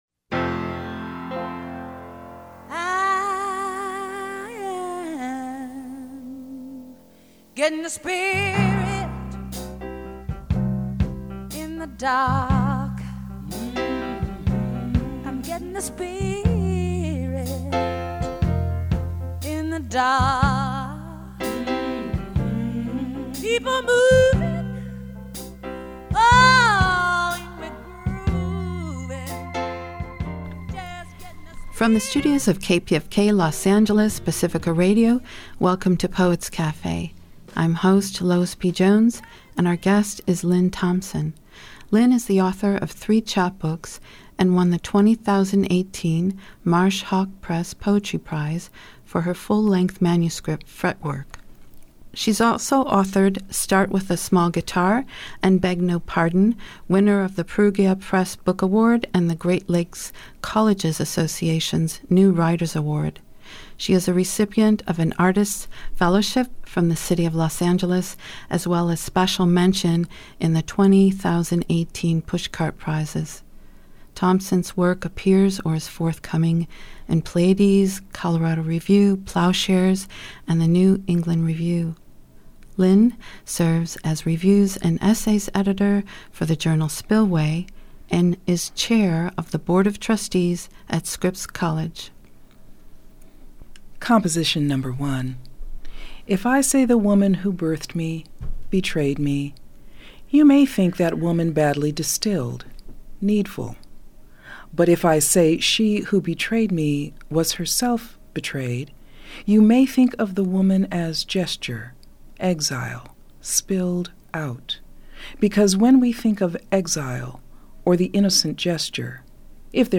The following interview
originally aired on KPFK Los Angeles (reproduced with permission).